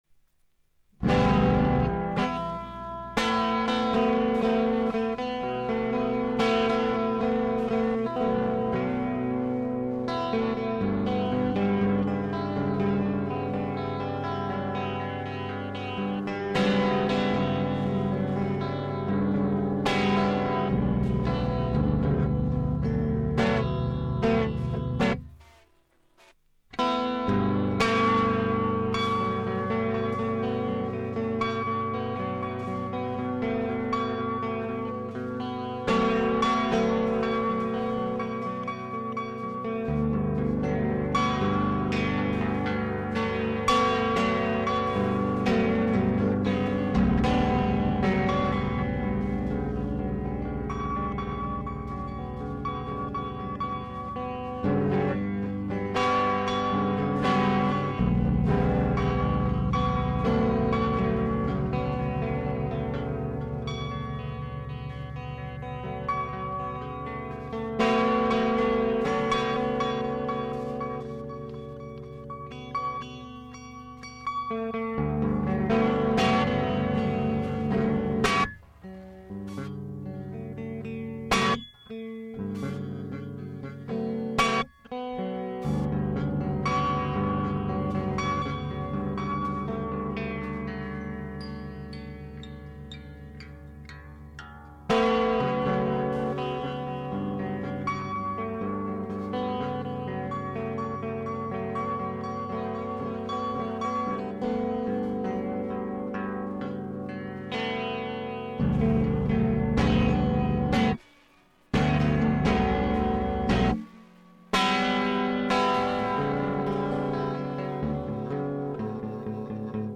この ギタークリーン だったのか、であって今までのは
ハムバッキンの力 だけだったか。